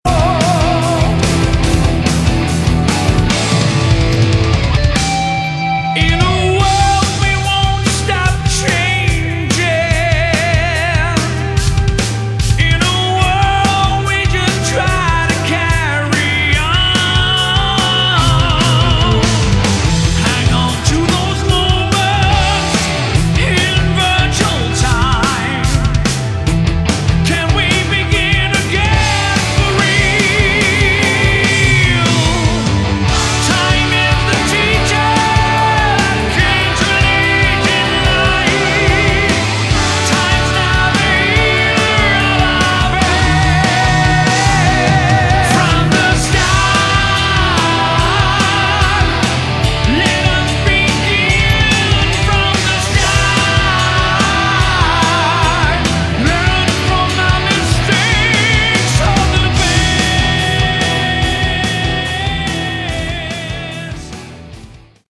Category: Hard Rock
bass, vocals
guitar, vocals
lead vocals
drums
guitars, vocals